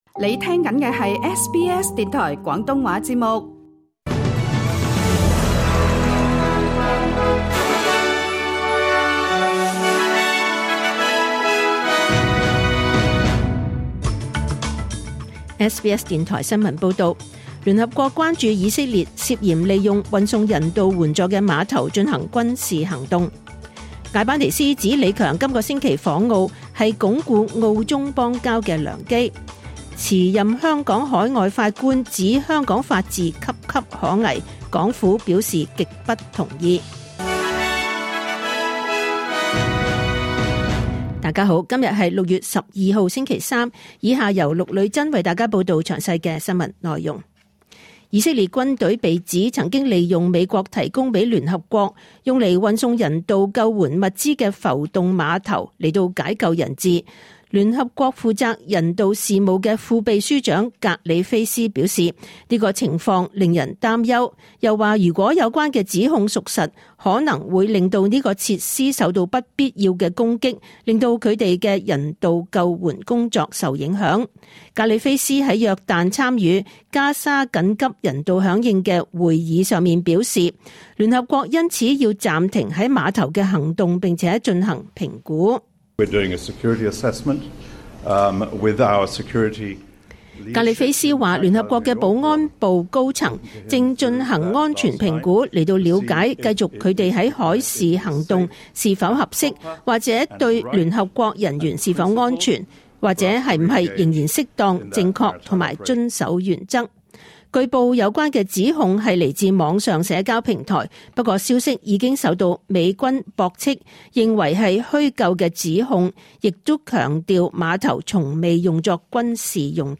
2024 年 6 月12 日 SBS 廣東話節目詳盡早晨新聞報道。
SBS廣東話新聞報道